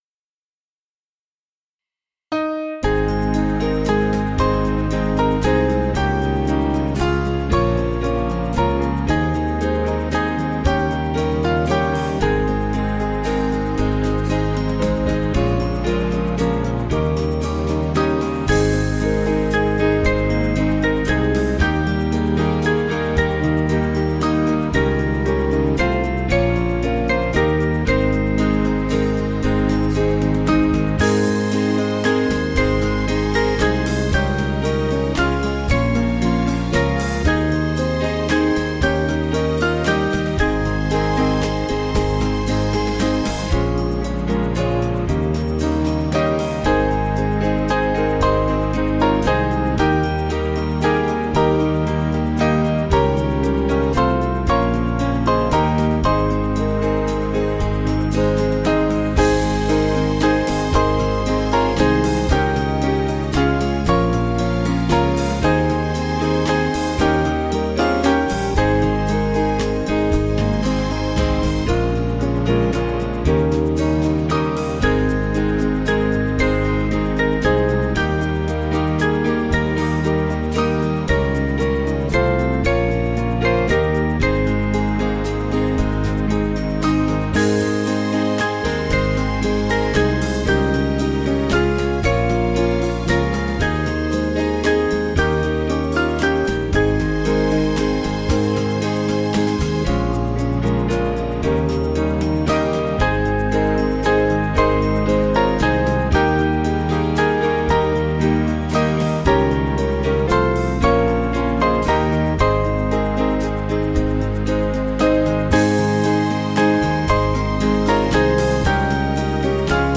Small Band
(CM)   4/Ab